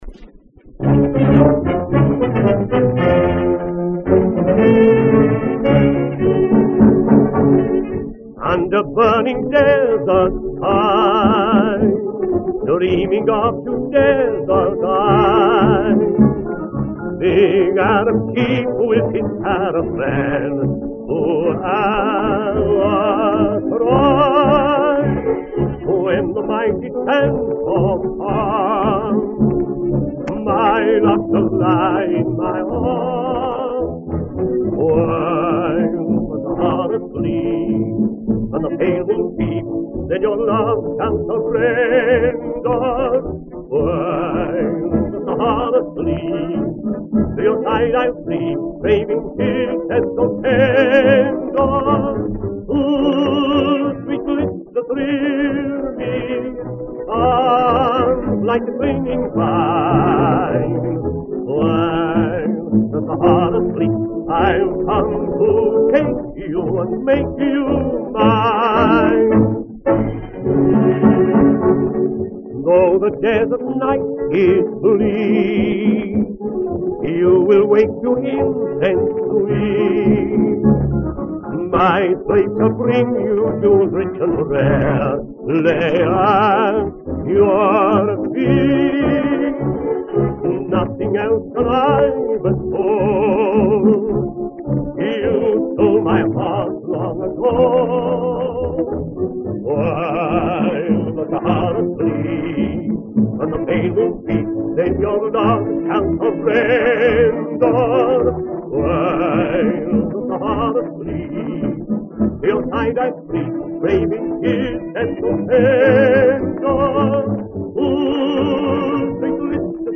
as “The Greatest Oriental Fox-Trot Ever Written”